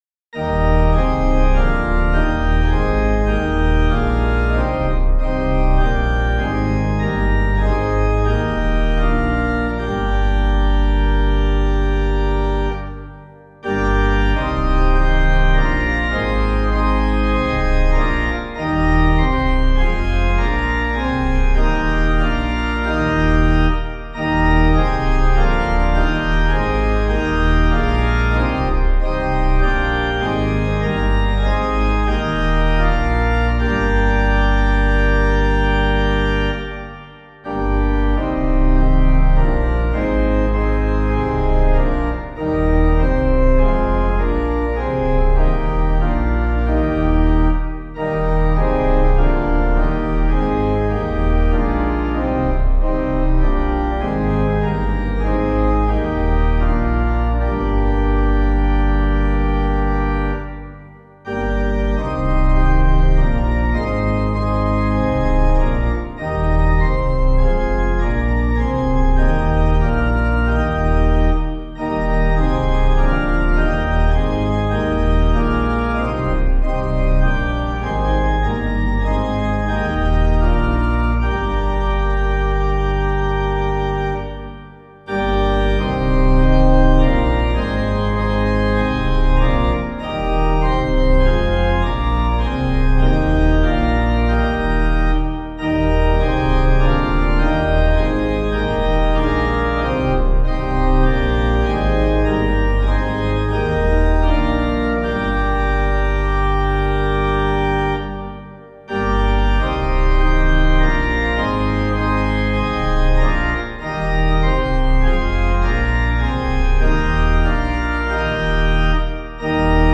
Composer:    Chant, mode IV, Sarum, 9th cent.
6 stanzas:
organpiano